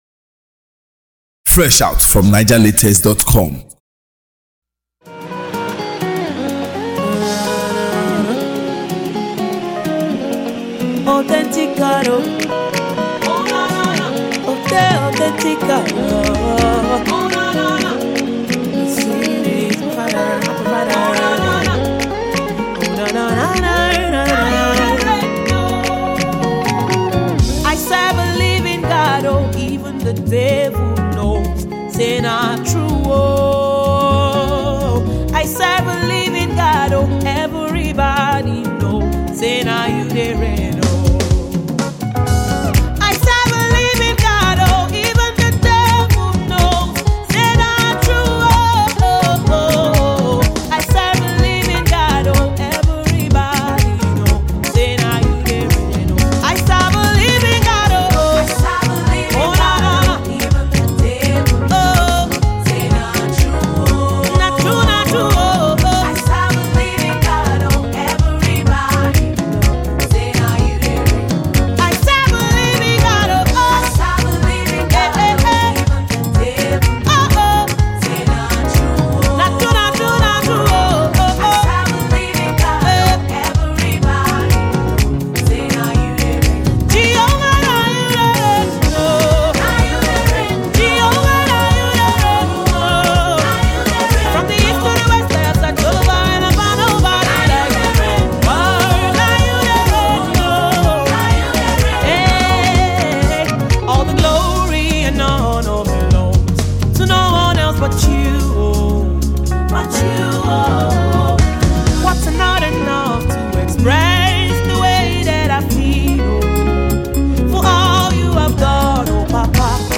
Raving gospel singer
Gospel artiste